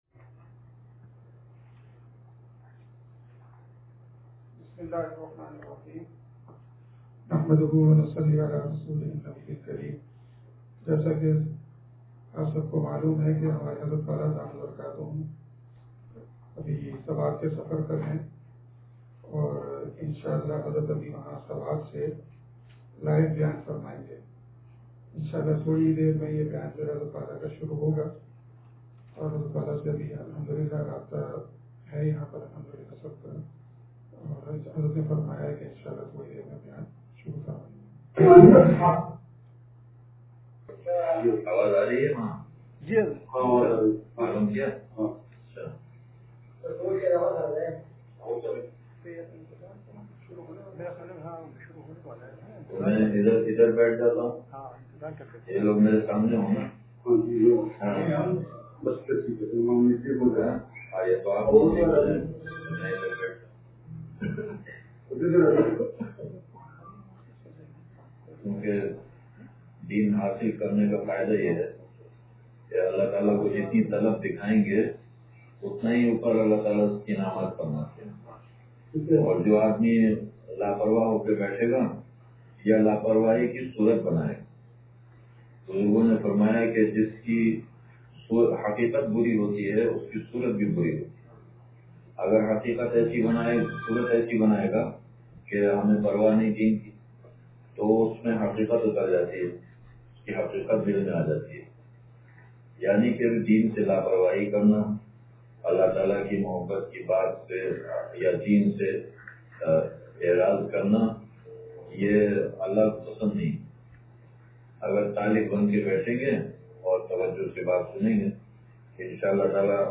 حضرتِ والا دامت برکاتہم العالیہ کا سوات سے لائیو بیان ہوا